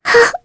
Mirianne-vox-Sad_kr.wav